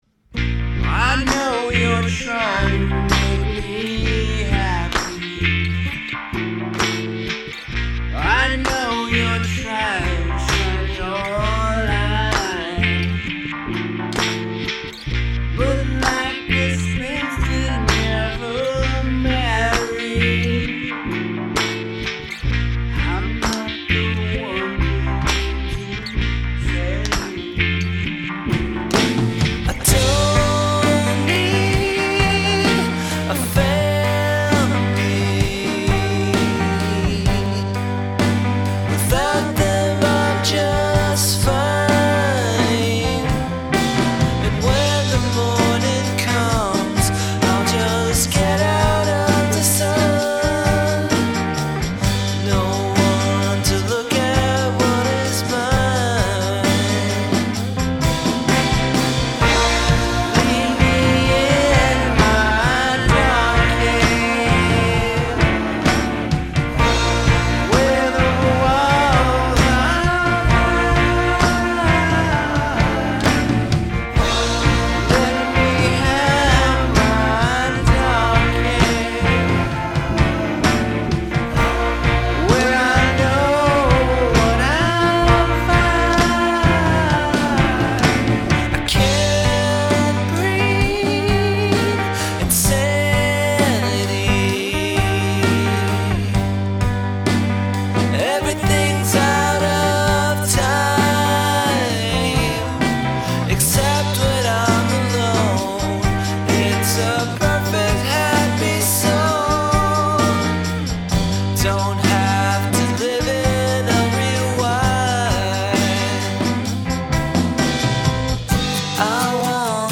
Make use of handclaps and snaps